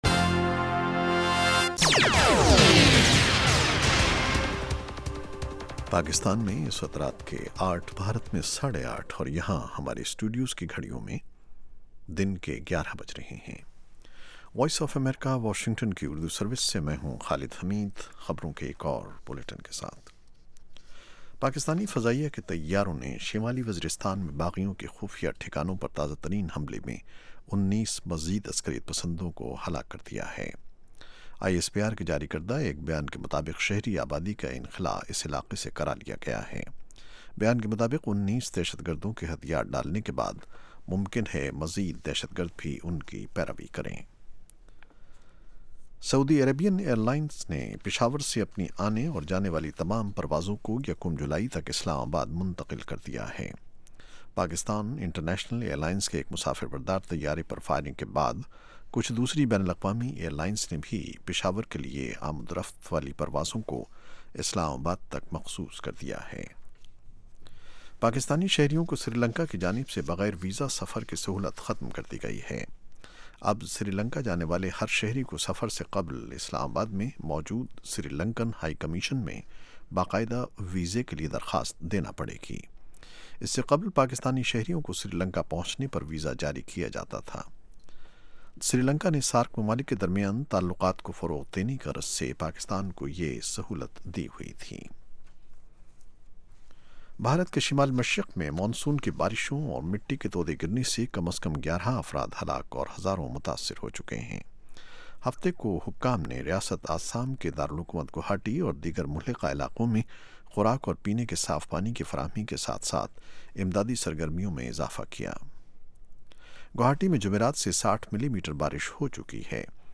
In The News: 8:00PM PST ایک گھنٹے دورانیے کے اس پروگرام میں خبروں کے علاوہ مہمان تجزیہ کار دن کی اہم خبروں کا تفصیل سے جائزہ لیتے ہیں اور ساتھ ہی ساتھ سننے والوں کے تبصرے اور تاثرات بذریعہ ٹیلی فون پیش کیے جاتے ہیں۔